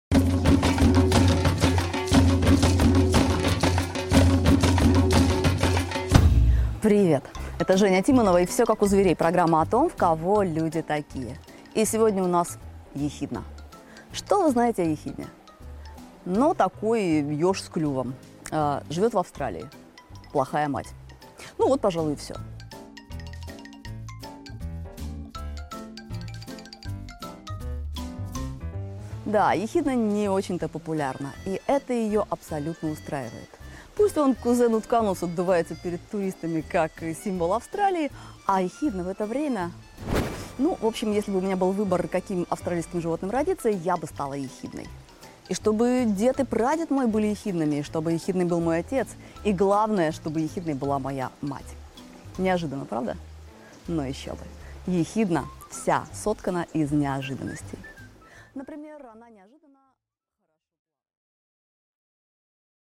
Аудиокнига Слава Матери-ехидне!